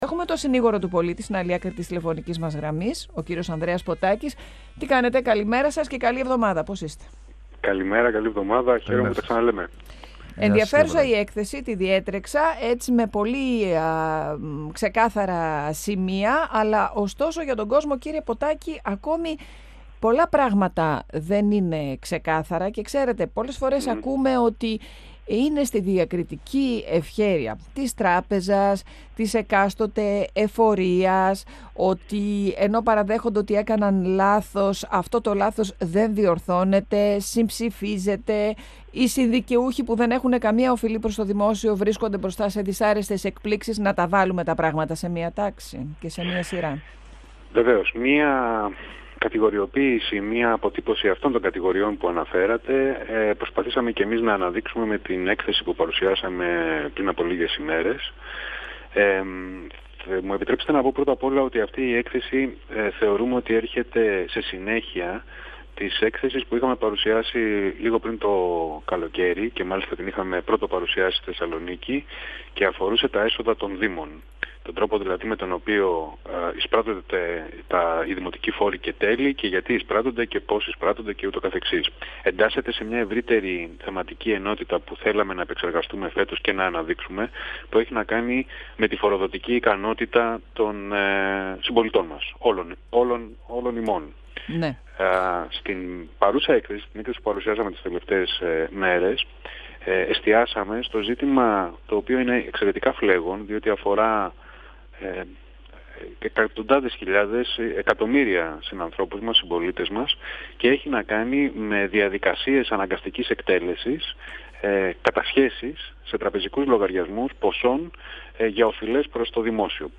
Η πολιτεία πρέπει να προχωρήσει σε διευκρινιστικές εγκυκλίους για να σταματήσουν οι στρεβλώσεις που παρατηρούνται στις κατασχέσεις τραπεζικών λογαριασμών προσώπων που έχουν οφειλές στο δημόσιο. Όπως τόνισε μιλώντας στον 102 φμ ο Συνήγορος του Πολίτη Ανδρέας Ποττάκης, στα γραφεία του Συνηγόρου φτάνουν πολλά παράπονα για κατασχέσεις επιδομάτων και επιδοτήσεων, για δεσμεύσεις λογαριασμών με συνδικαιούχους, και έλλειψη ενημέρωσης των δικαιούχων για επικείμενη δέσμευση και κατάσχεση λογαριασμών.
Συνεντεύξεις